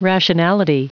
Prononciation du mot rationality en anglais (fichier audio)
Prononciation du mot : rationality